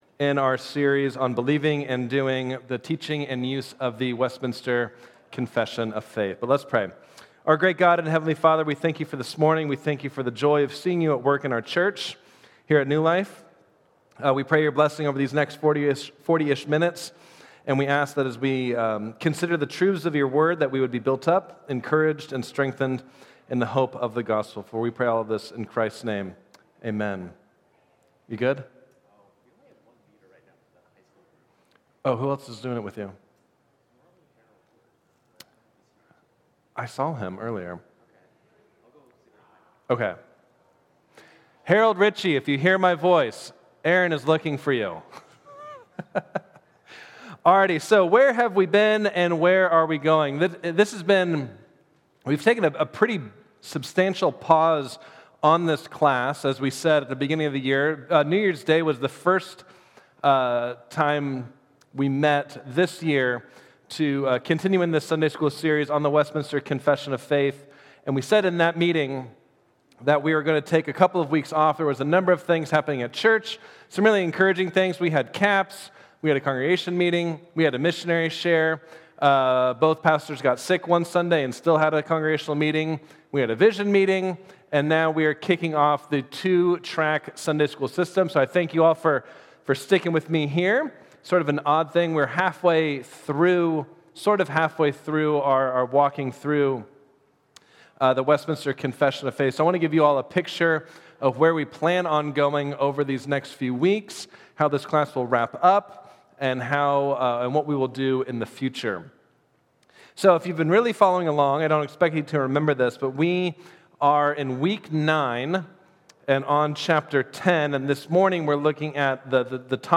Westminster Confession Sunday School | New Life Presbyterian Church of La Mesa